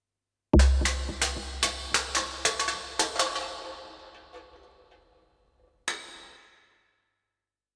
The choice of surfaces,acoustic and microphone placement chosen with as much care as the photographer took with the location and lighting of the subject. Aluminium cans, steel cans, cans thrown from balconies, rolled down stairs or crushed by feet. And a framing context, perhaps a fast rhythmic pulse running under it.
perfected-coke-can.mp3